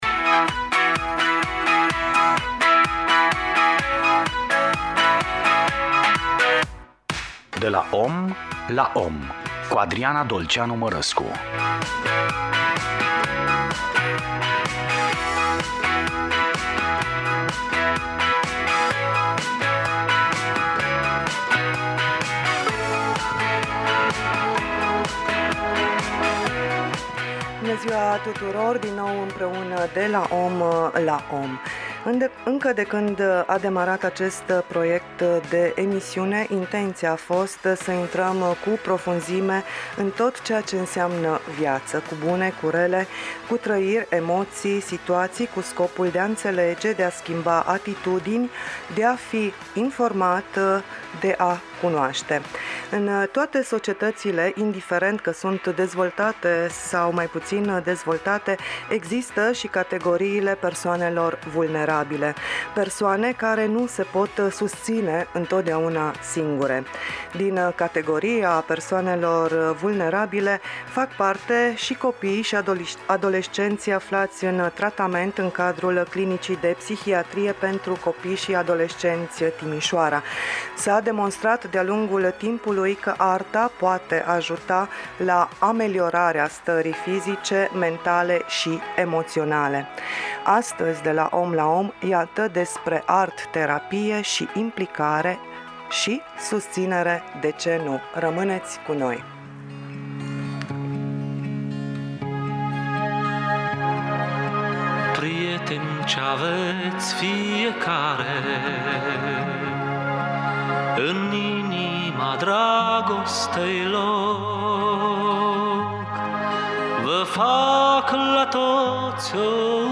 Podcast: Dialog despre art terapie